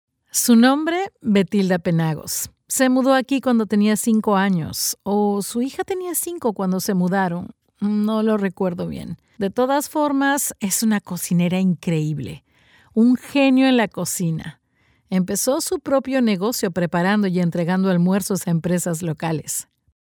locutora de español neutro